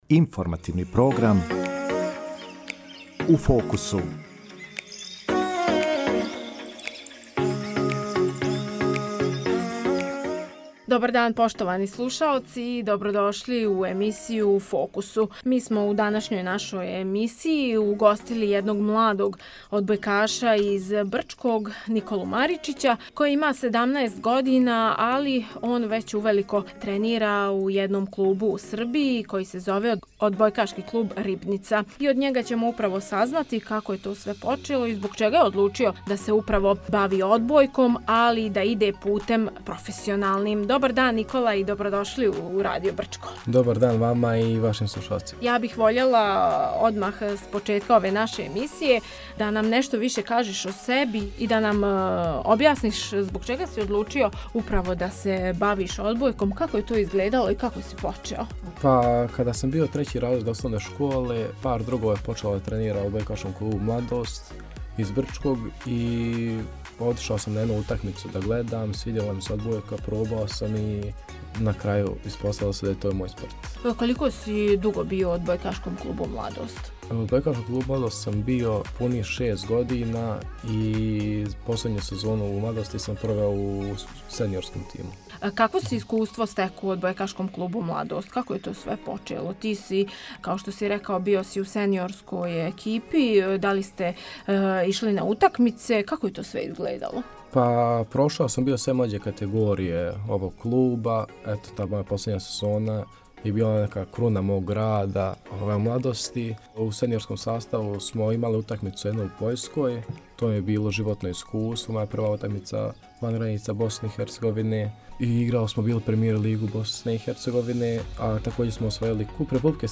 У емисији “У фокусу” разговарали смо